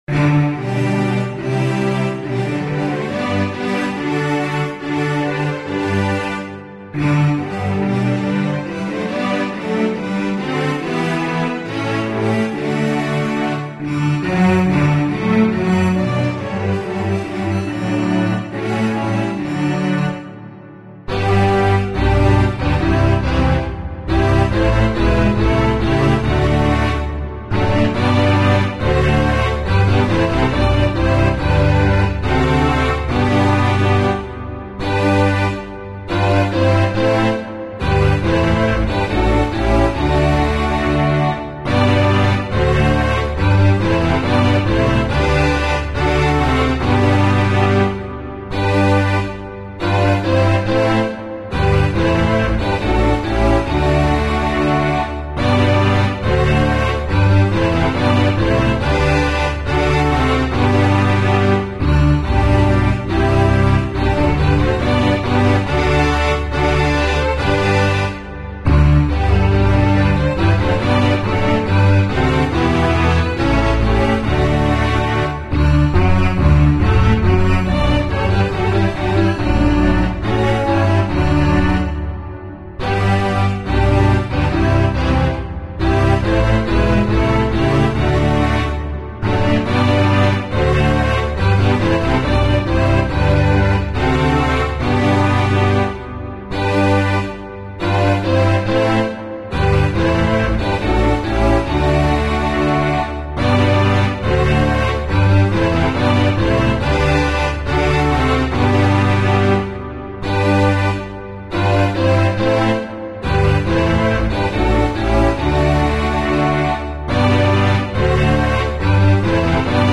DUBAI —A British warship tried but failed to prevent Iran’s Islamic Revolutionary Guard Corps from seizing a British tanker in the Strait of Hormuz last week, intercepted radio communications show, fueling a wave of recriminations in London on Sunday over who was to blame for the incident last week.
In recordings obtained by the shipping consultancy Dryad Global and posted on its website Sunday, a member of the Revolutionary Guard is heard ordering the British-flagged Stena Impero tanker to divert course toward Iran.
A British naval officer interrupts, telling the Stena Impero that it has the right to proceed through the waterway.